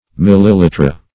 millilitre - definition of millilitre - synonyms, pronunciation, spelling from Free Dictionary
Milliliter \Mil"li*li`ter\, Millilitre \Mil"li*li`tre\, n. [F.